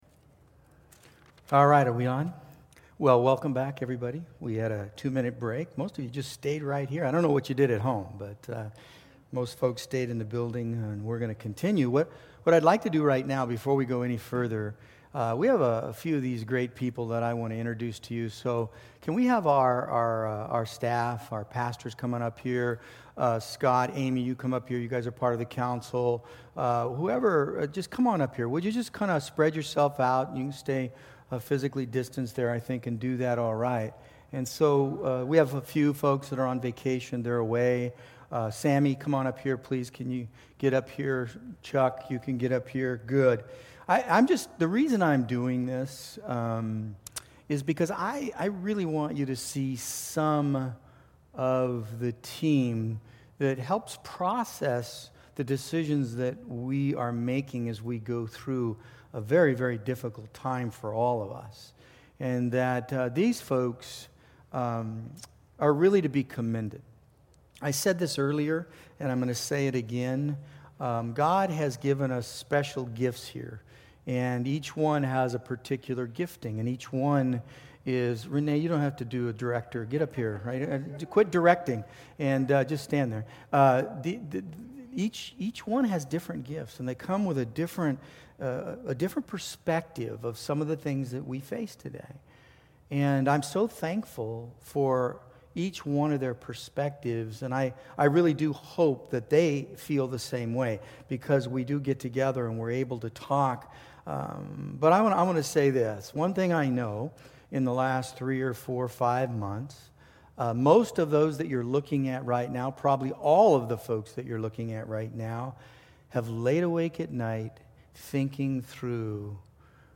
Congregational Address - Week One